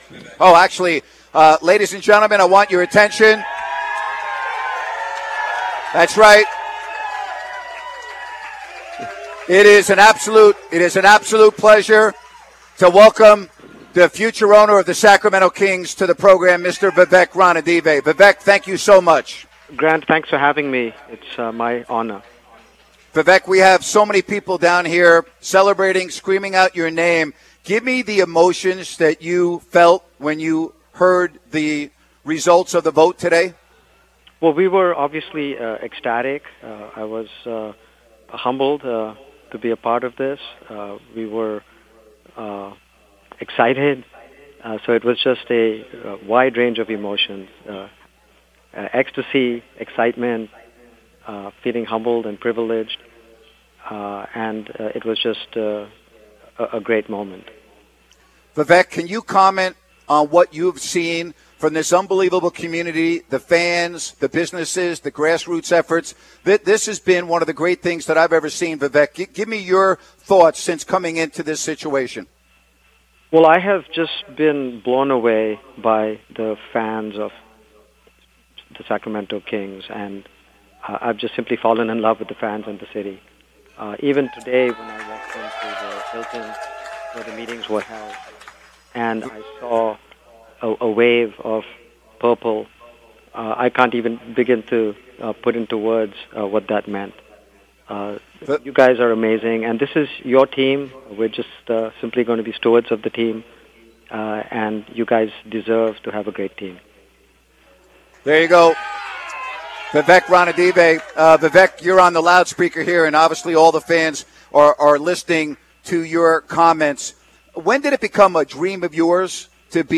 Vivek Ranadivé joined Grant Napear, from his live broadcast at Firestone Public House in Downtown Sacramento. Vivek talked about how he is very proud to be the new owner of the team, and mentioned he can't wait to meet all of the wonderful fans of Sacramento.
vivek-ranadive-interview.mp3